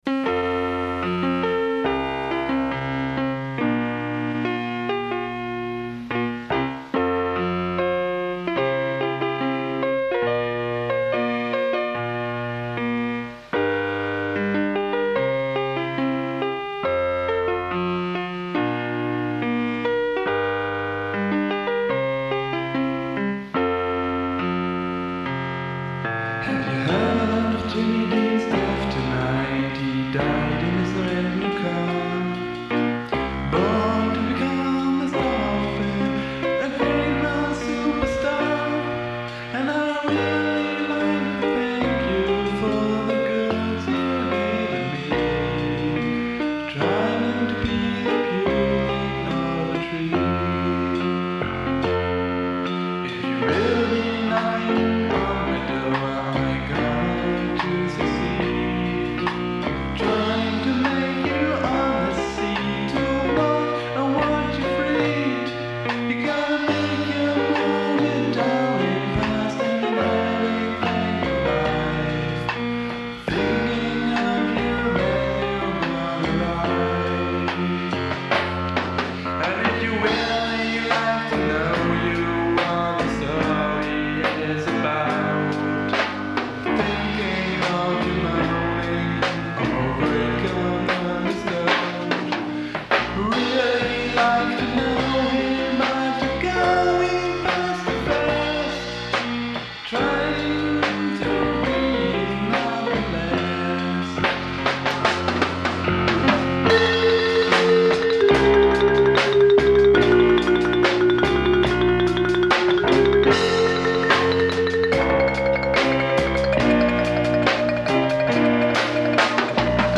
Ich bin mir nicht sicher mit welcher Mehrspur-Maschine ich das aufgenommen habe, auf jeden Fall spiele ich Schlagzeug, Piano, Bass (am Keyboard) und singe (mit gaaanz viel Hall) und habe als besonderes Highlight noch ein Marimba-Solo eingebaut.
Den Text gab es nicht so richtig, ich glaube ich hatte nur die namensgebende Textzeile und dann noch so ein bißchen was drum herum, wohl auch etwas Fantasie-Englisch dabei.
Die Melodie und Harmonien sind ja eigentlich ganz nett, hätte ich mir schon die Mühe machen können, mal einen Text zu schreiben.
Auch beim Zeitpunkt bin ich mir nicht mehr sicher, ich schätze auf Mitte der 90er, als ich noch keinen Bass hatte.